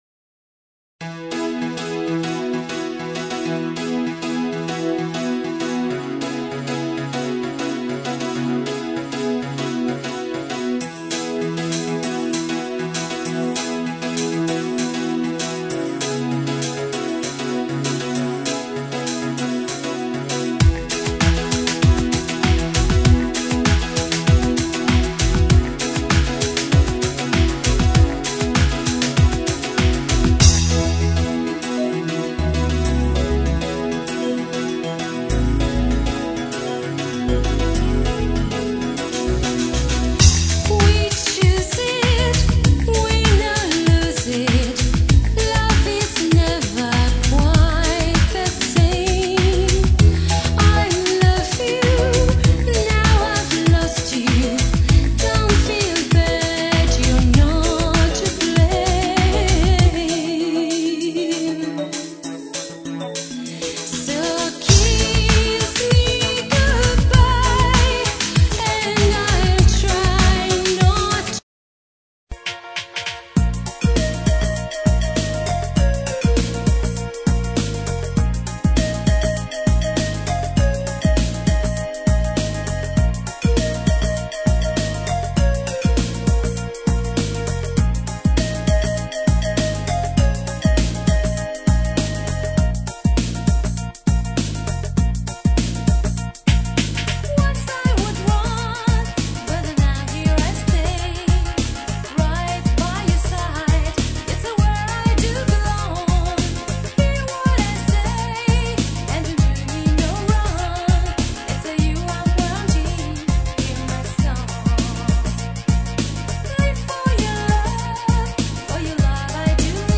当前位置 > 首页 >音乐 >CD >R＆B，灵魂
※試聴は大幅に音質を落しています。実際の音質はもっと良いです。
収録曲はすべてカバー曲です。